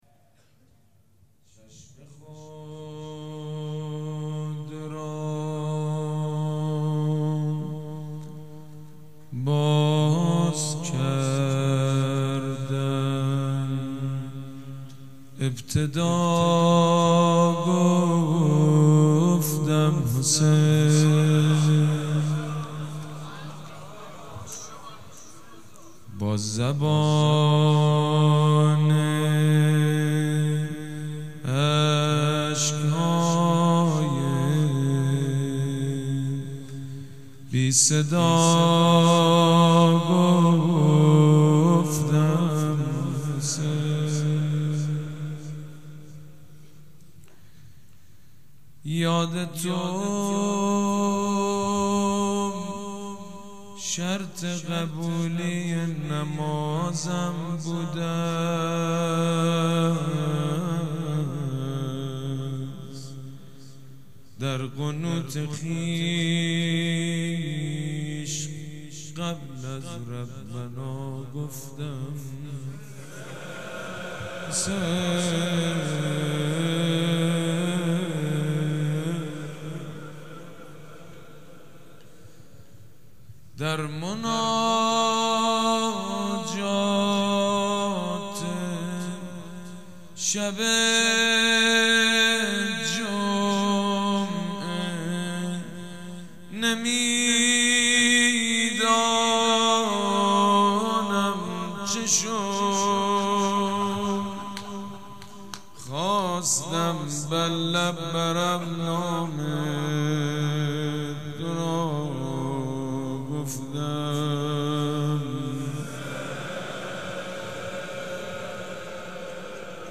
مناسبت : شب پنجم محرم
مداح : سیدمجید بنی‌فاطمه قالب : روضه